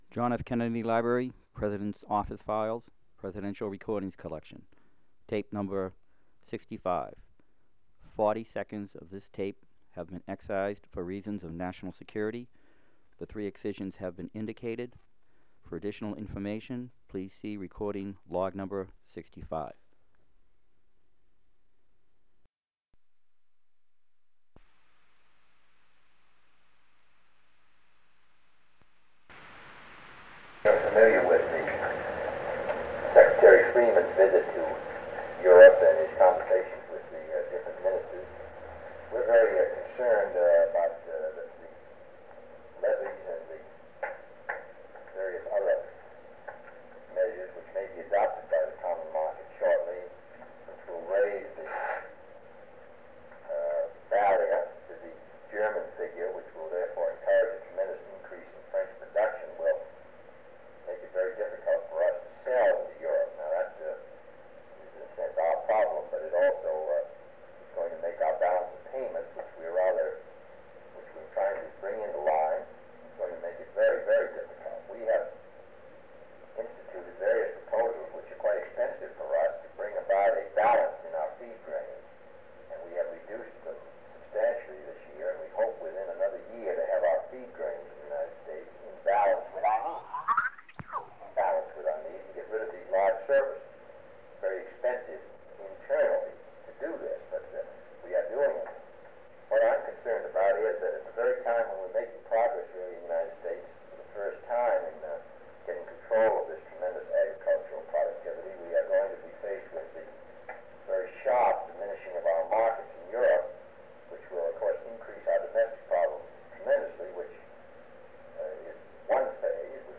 Secret White House Tapes | John F. Kennedy Presidency Meeting with Belgian Foreign Minister Paul-Henri Spaak Rewind 10 seconds Play/Pause Fast-forward 10 seconds 0:00 Download audio Previous Meetings: Tape 121/A57.